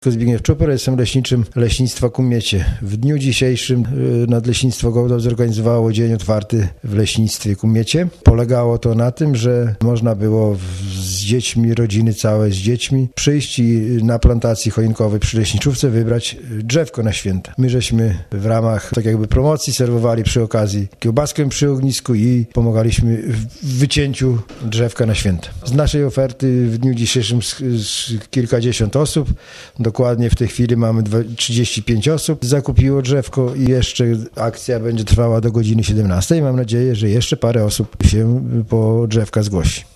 mówi leśniczy